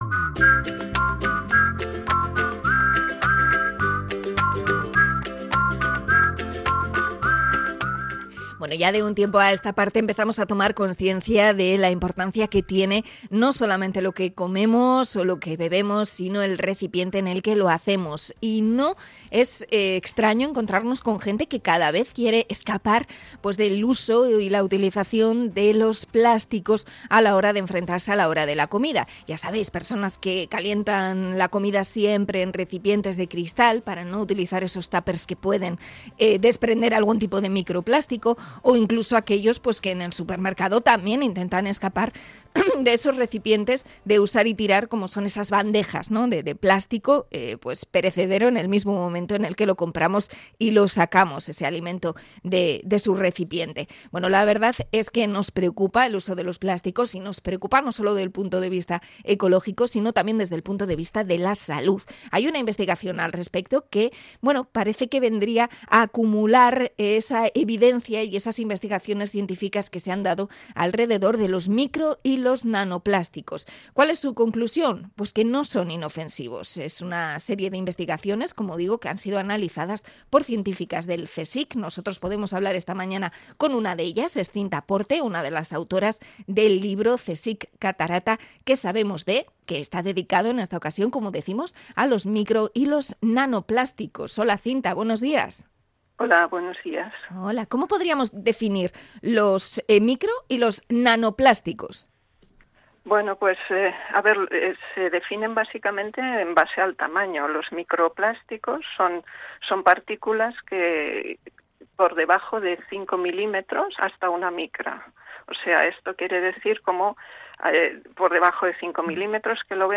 Entrevista al CSIC por las investigaciones sobre los micro y nanoplásticos